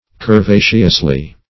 curvaceously.mp3